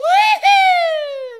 Mario boosting in Mario Kart 8.
MK8_Mario_-_Whoohooo!.oga.mp3